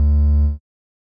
Techmino/media/sample/bass/6.ogg at beff0c9d991e89c7ce3d02b5f99a879a052d4d3e